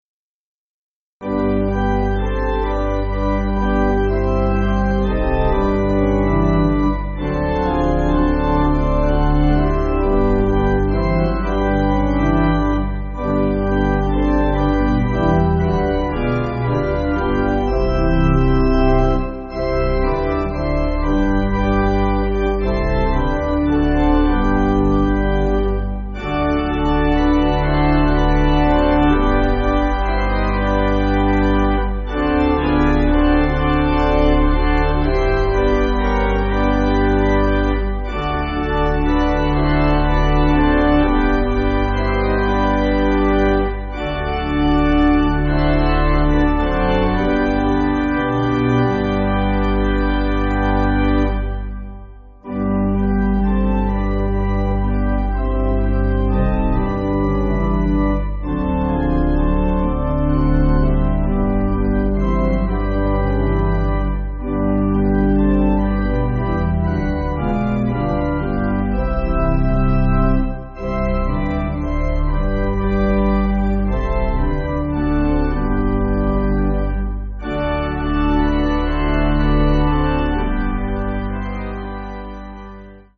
(CM)   4/G